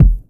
Boom-Bap Kick 65.wav